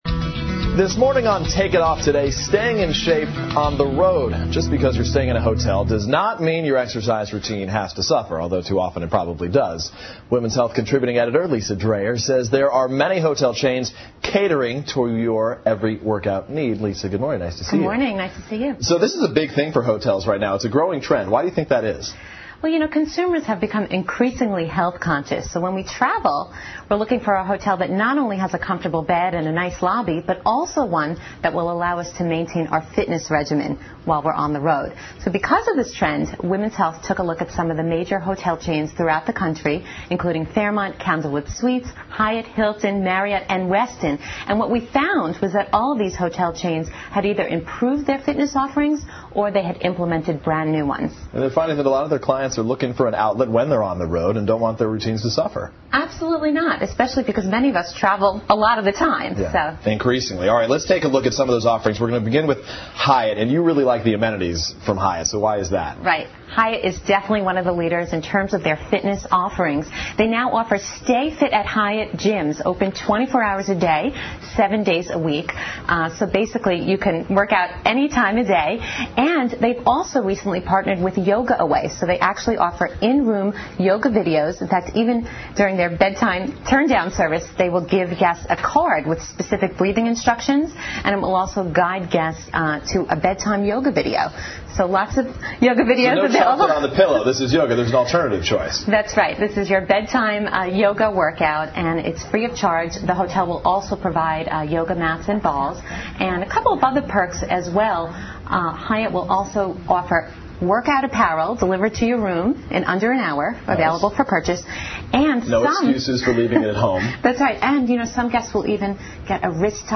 访谈录 Interview 2007-07-31&08-02, 出行锻炼两不误 听力文件下载—在线英语听力室